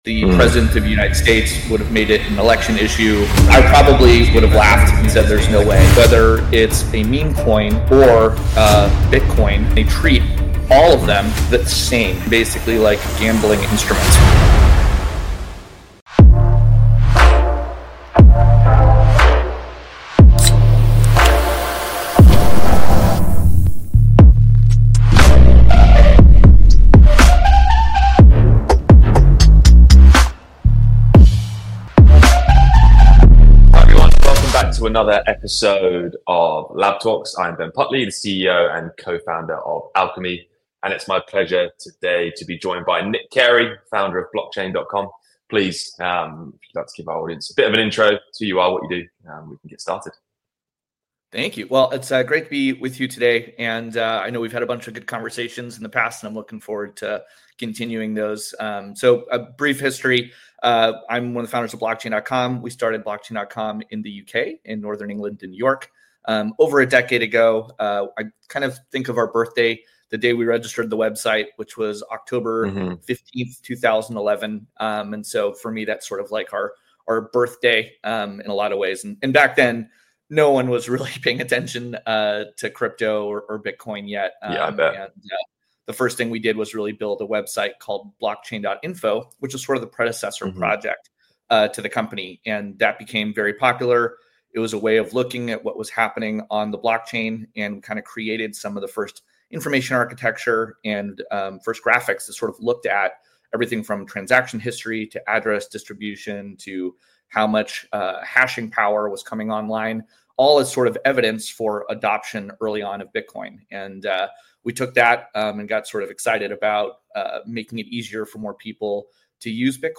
They explore the pros and cons of AI in advertising, innovations Kinesso is excited about, educating clients on emerging technologies like crypto wallets for identity, and the overall evolving ad tech landscape. It's an insightful conversation covering key challenges and opportunities in digital advertising today.